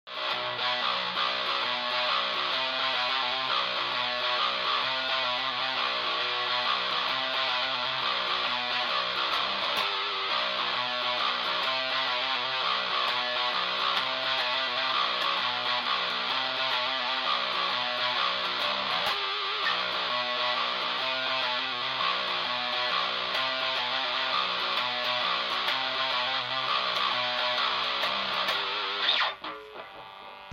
Me medesimo - Esercizio riff (chitarra) di "Our truth" by Lacuna Coil